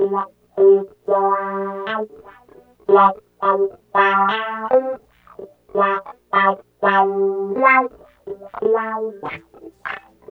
44 GUITAR -L.wav